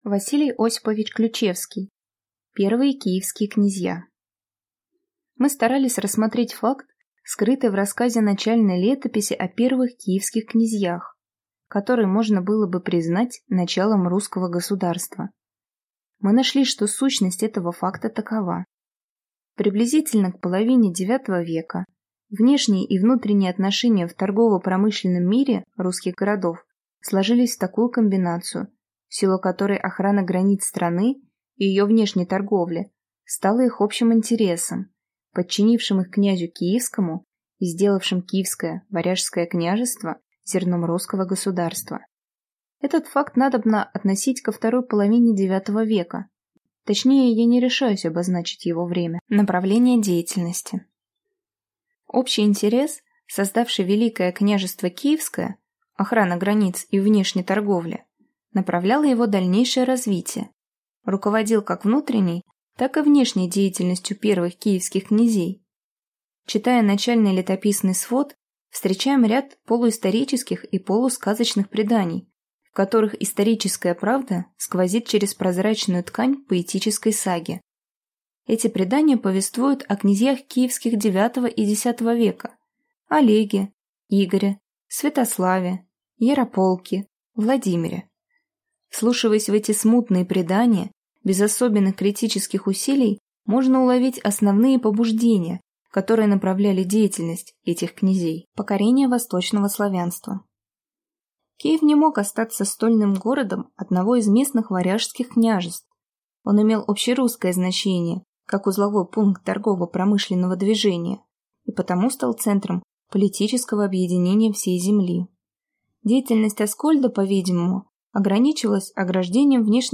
Аудиокнига Первые Киевские князья | Библиотека аудиокниг